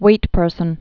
(wātpûrsən)